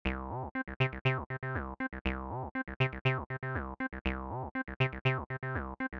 Slide Between Notes for Liquid Movement
3-2_Slides_TB-303_Example.mp3